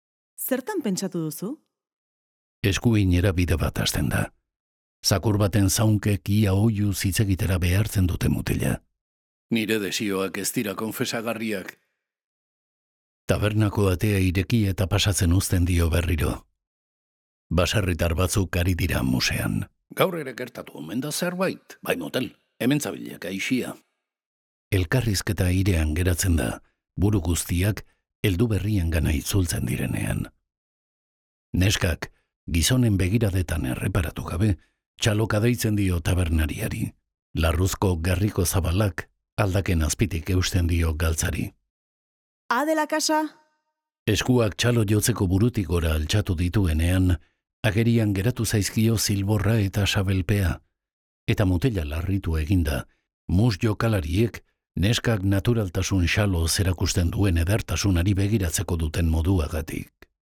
Euskal idazleen audioliburuak paratu ditu sarean eitb-k